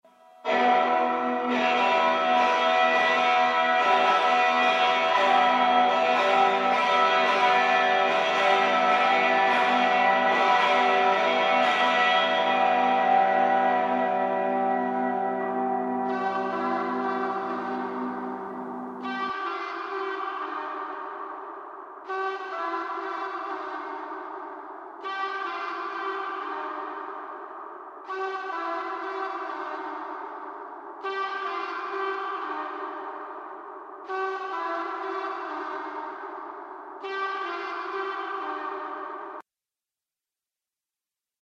Reverb. It is the sonic equivalent of DoF in fottigraffy. Masks all sorts of schoolboy errors.)